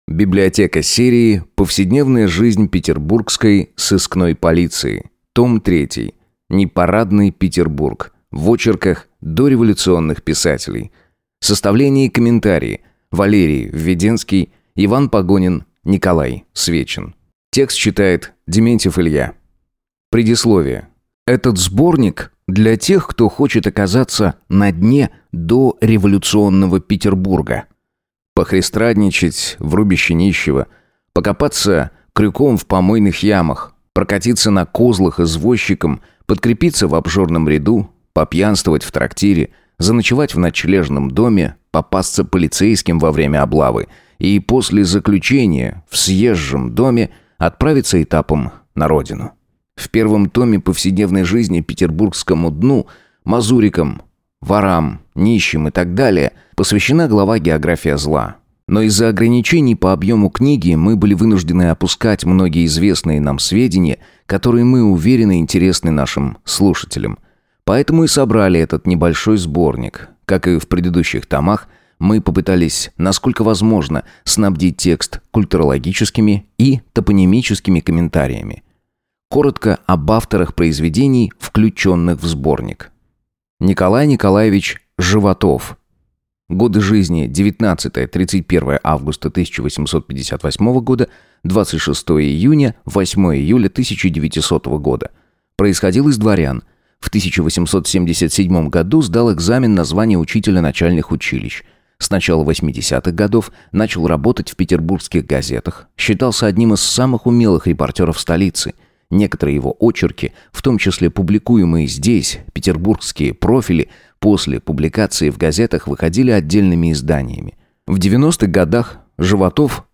Аудиокнига Непарадный Петербург в очерках дореволюционных писателей | Библиотека аудиокниг